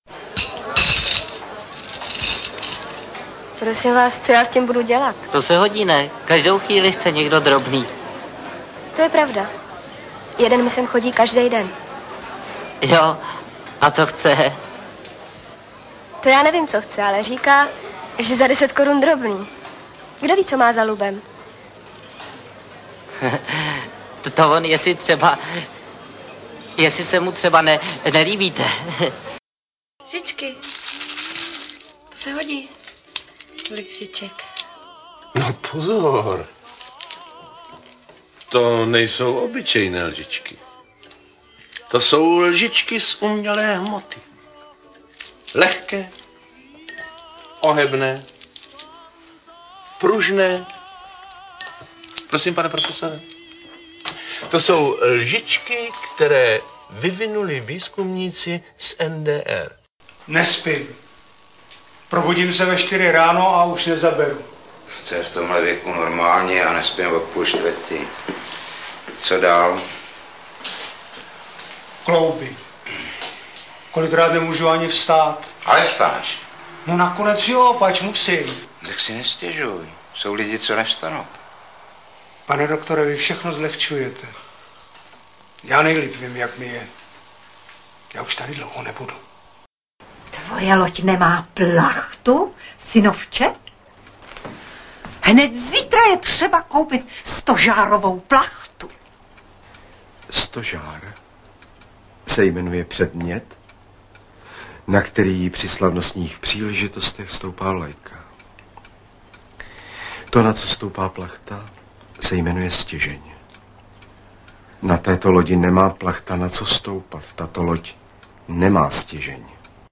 zvukových ukázek 10 českých filmů.